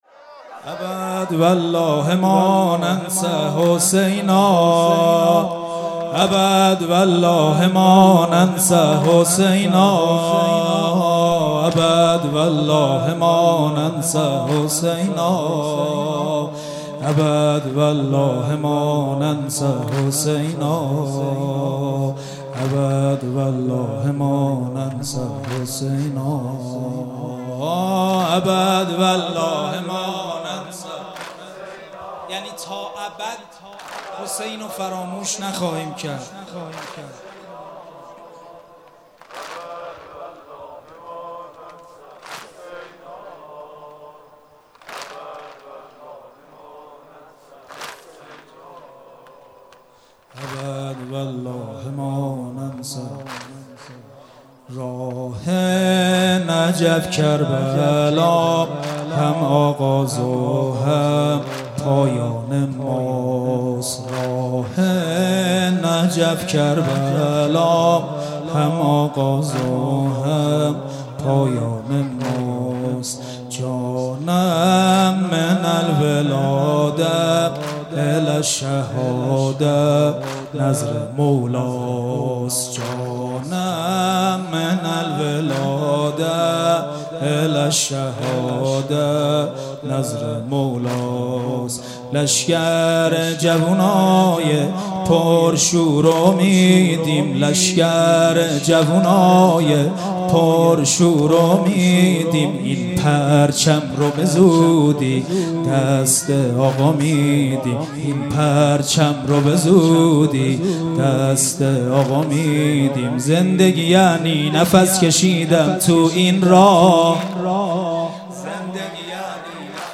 هیئت محبین اهل بیت شهرستان دلفان - مداحی